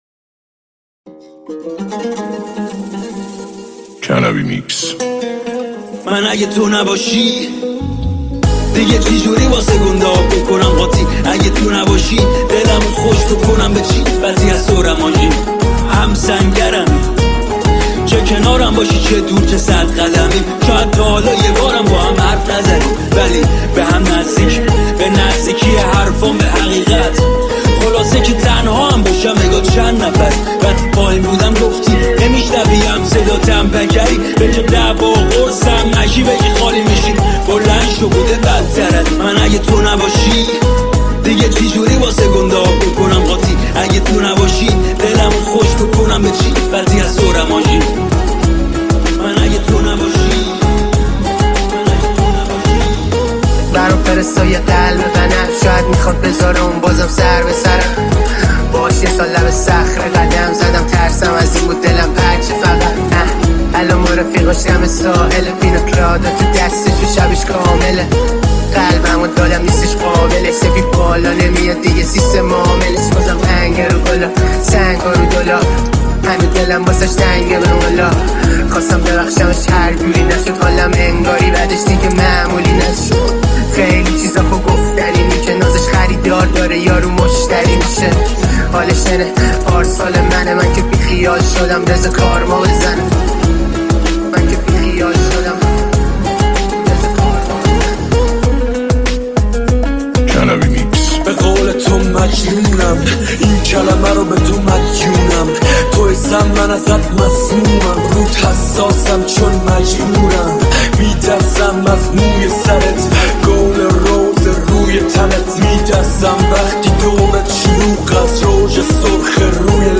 • ریمیکس جدید